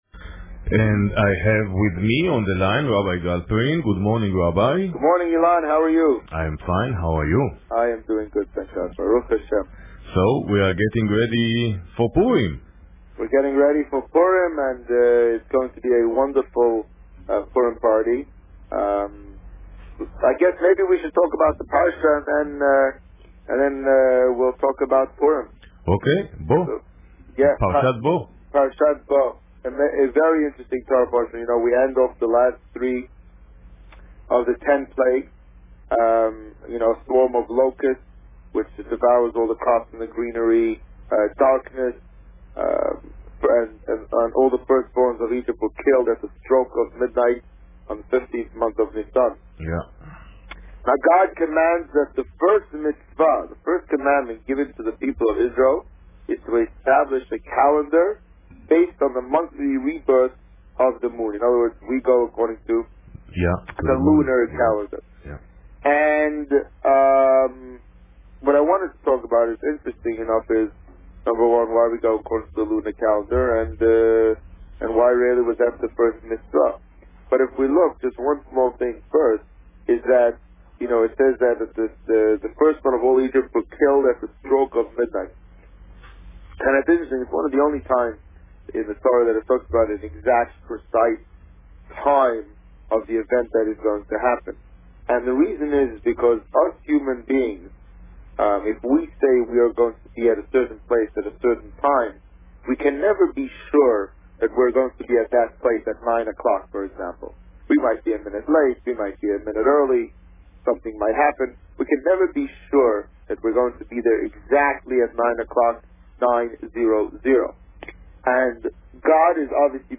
Parsha Bo and preparations for Purim Published: 17 January 2013 | Written by Administrator This week, the Rabbi spoke about Parsha Bo and preparations for Purim. Listen to the interview here .